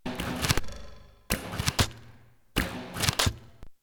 arrows.wav